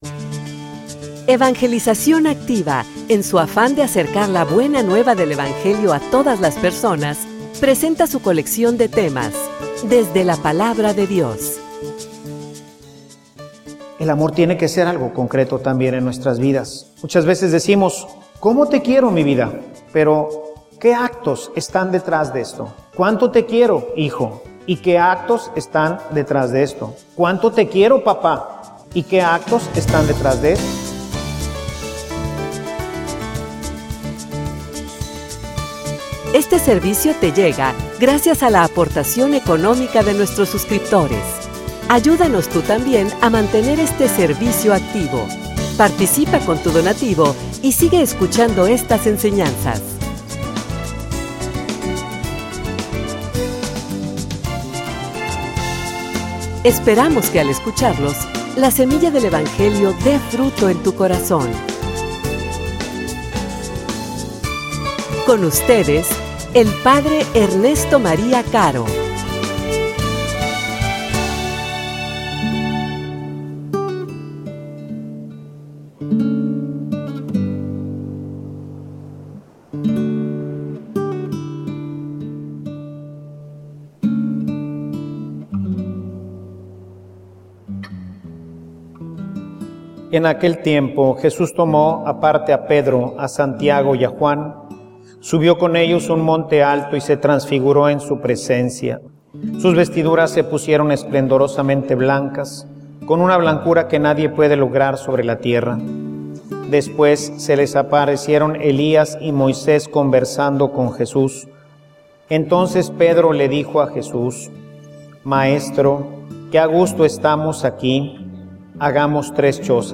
homilia_El_amor_pasa_por_la_cruz.mp3